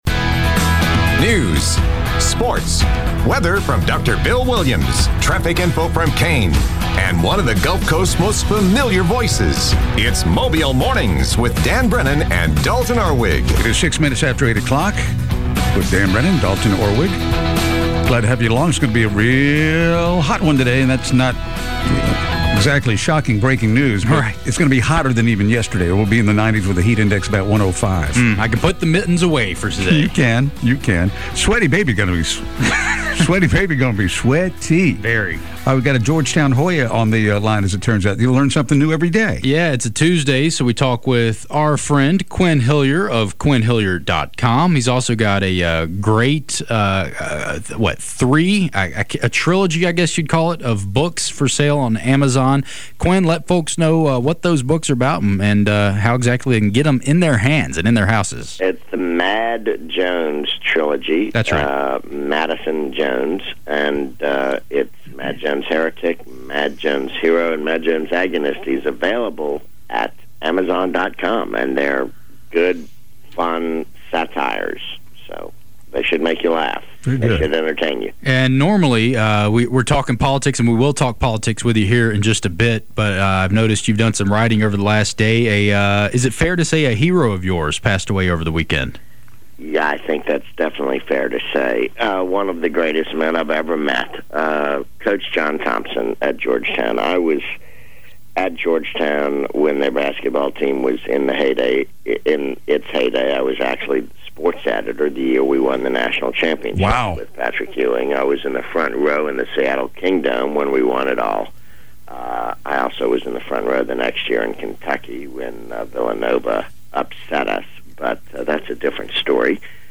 report on local news and sports.